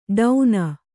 ♪ ḍauna